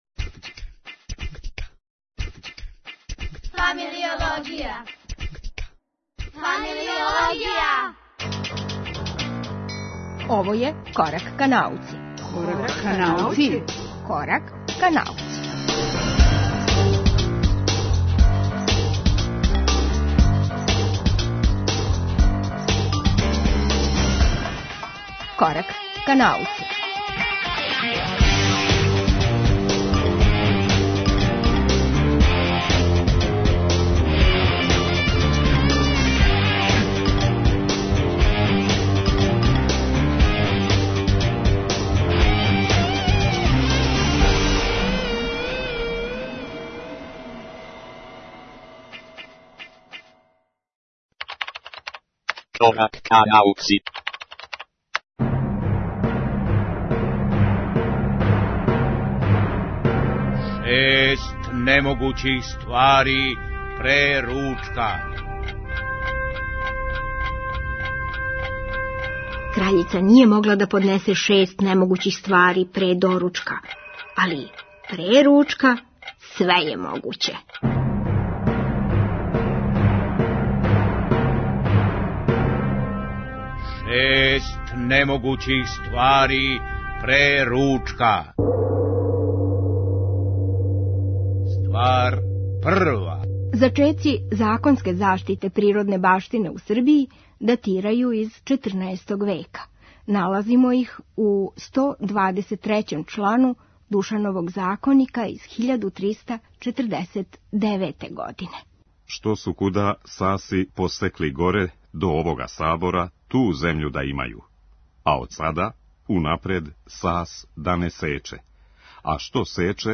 Актуелно - разговор о дану еколошког дуга и пројектима WWF-а;